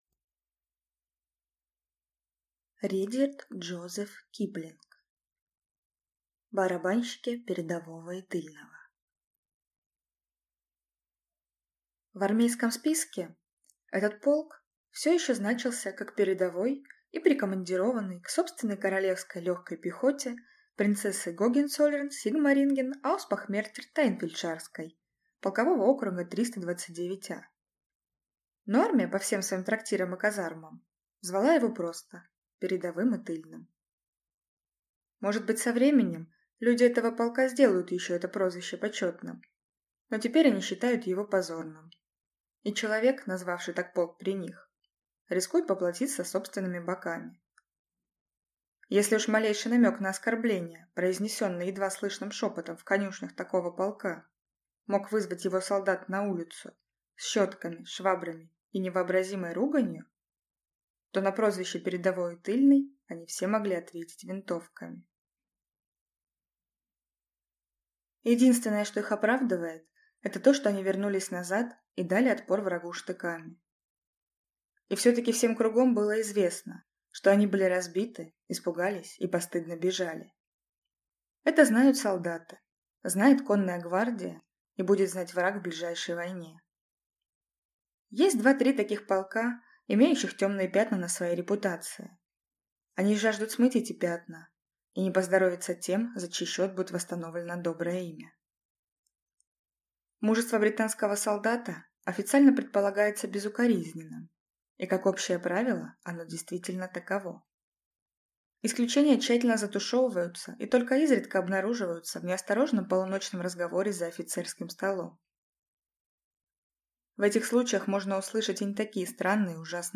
Аудиокнига Барабанщики «Передового и Тыльного» | Библиотека аудиокниг